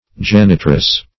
Search Result for " janitress" : The Collaborative International Dictionary of English v.0.48: Janitress \Jan"i*tress\, Janitrix \Jan"i*trix\, n. [L. janitrix.
janitress.mp3